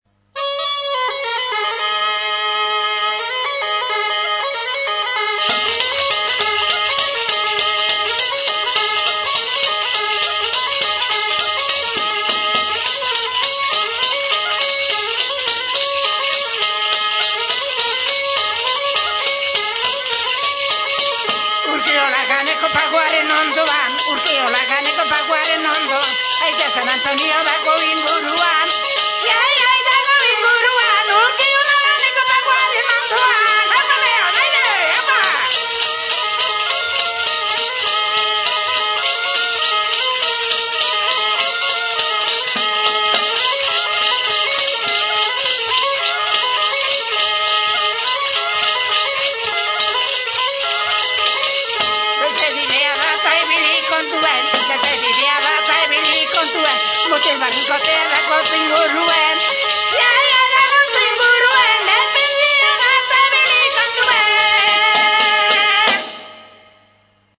Music instrumentsAlboka
Aerophones -> Reeds -> Single fixed (clarinet)
ALBOKA
Klarinete bikoitza da.